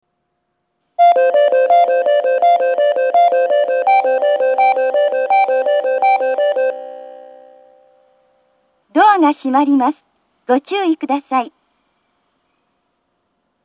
発車メロディーは１回スイッチを扱うと必ず最後まで流れますが、スイッチを扱わないこともあります。
２番線発車メロディー